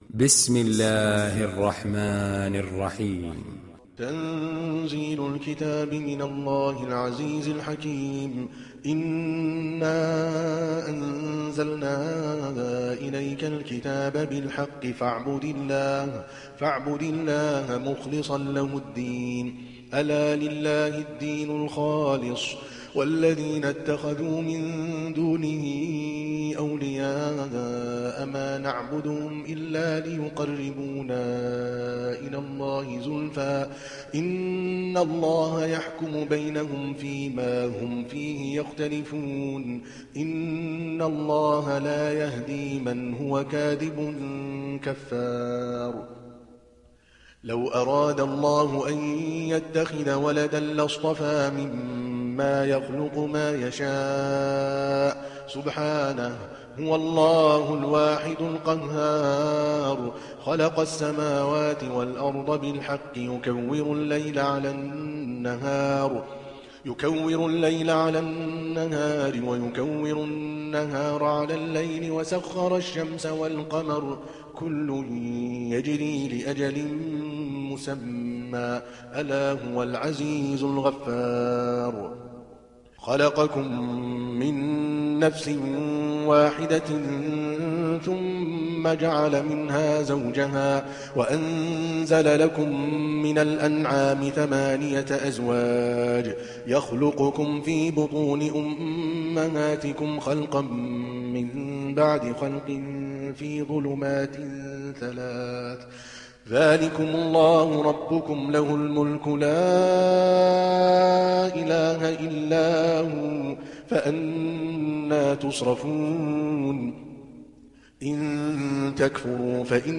دانلود سوره الزمر mp3 عادل الكلباني (روایت حفص)